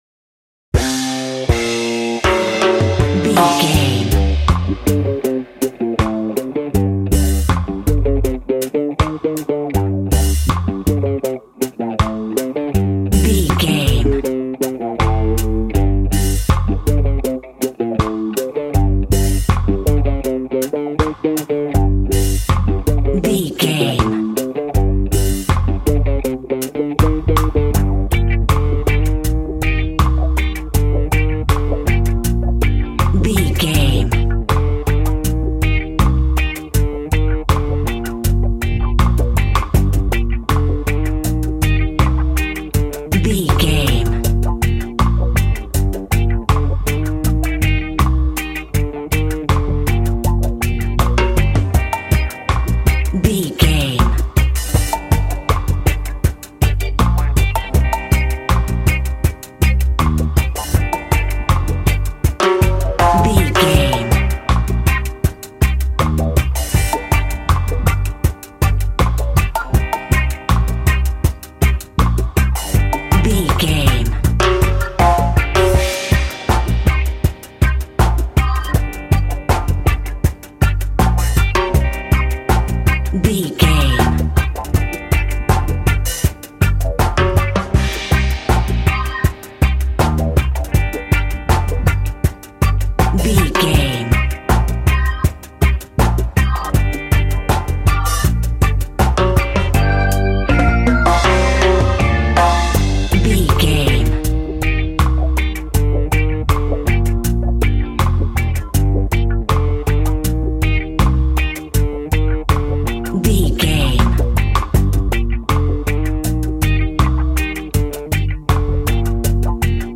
Aeolian/Minor
cheerful/happy
mellow
drums
electric guitar
percussion
horns
electric organ